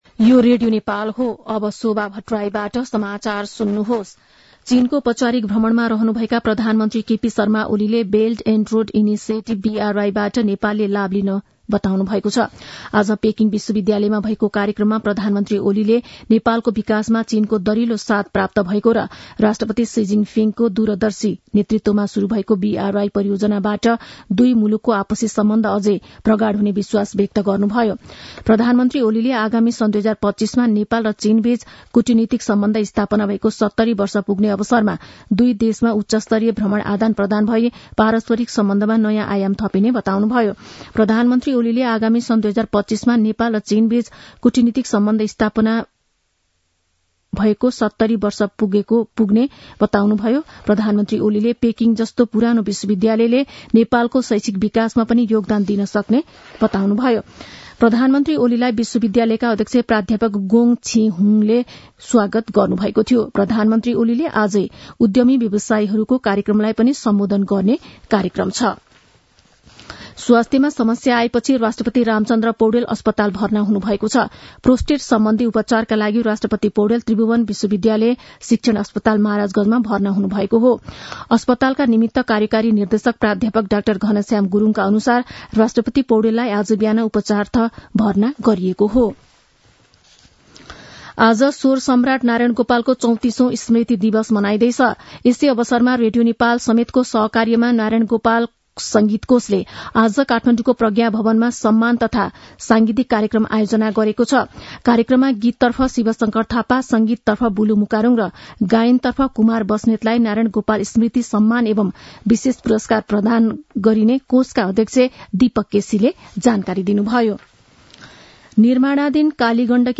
मध्यान्ह १२ बजेको नेपाली समाचार : २० मंसिर , २०८१
12-am-nepali-news-1-2.mp3